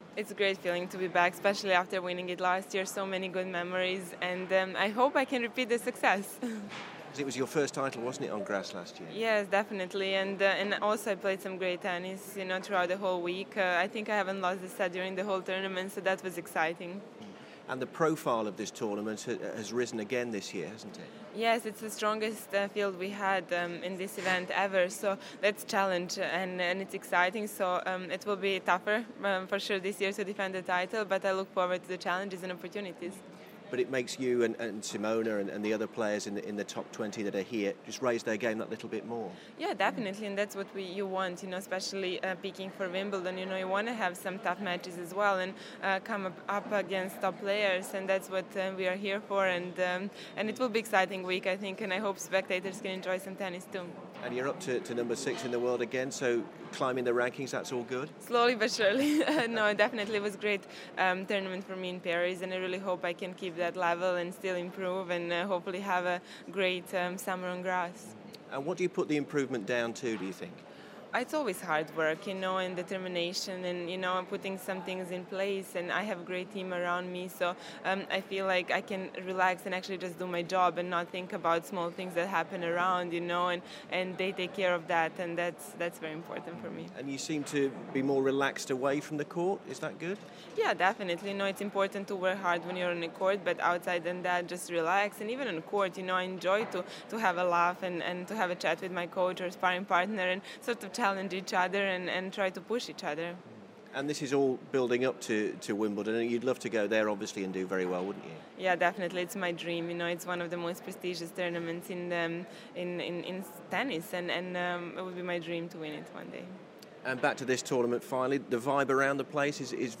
Defending champion Ana Ivanovic speaks